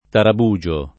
tarabugio [ tarab 2J o ]